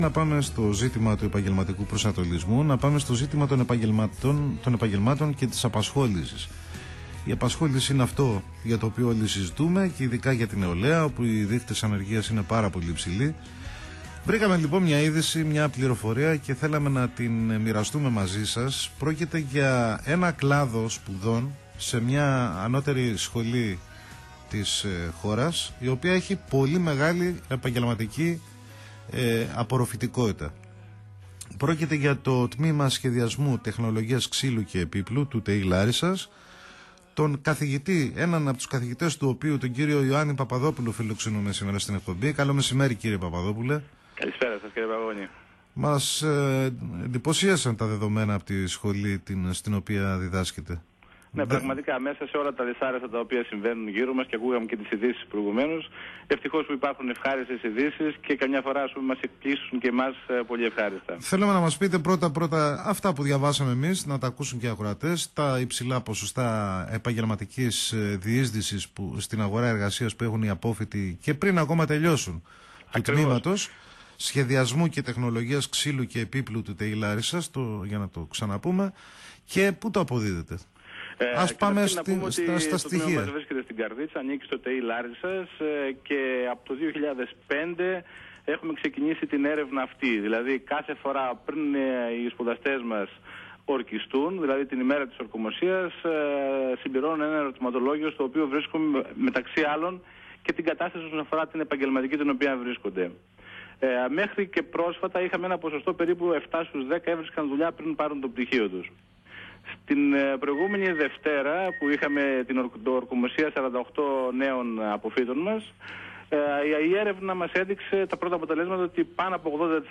Συνέντευξη
Interview_105_8.mp3